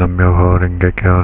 Reči namu (sanskrit) i mjo (kineski, ali izgovarano na japanski način) prilikom izgovora stapaju se tako da zvuče namjo.
daimoku.wav